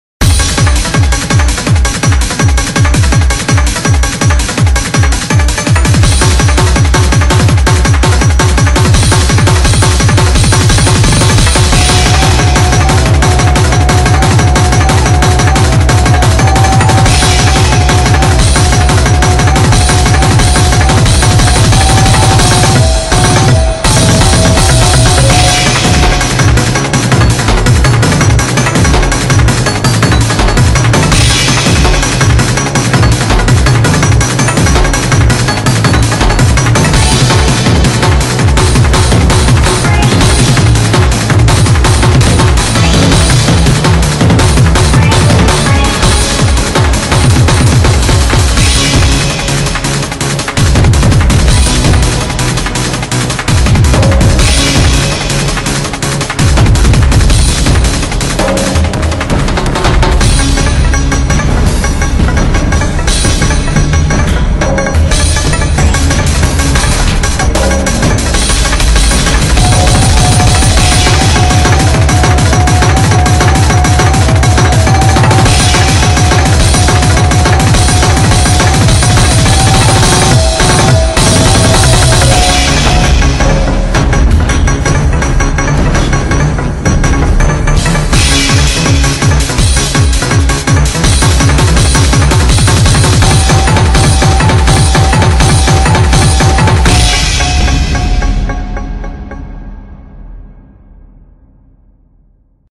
Audio QualityPerfect (High Quality)
H - Song is over 200BPM.
L - Song changes BPM.